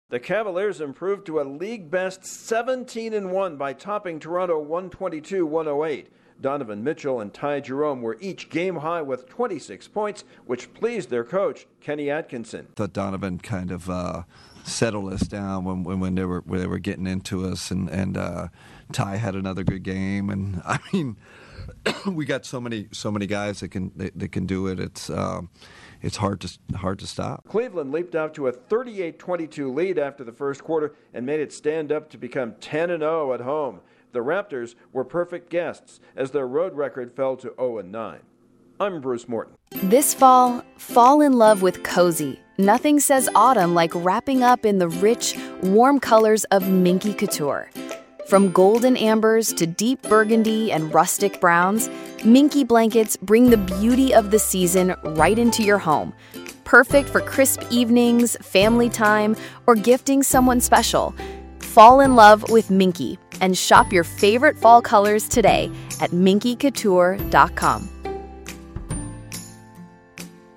An NBA game pitting Toronto at Cleveland had a very predictable outcome. Correspondent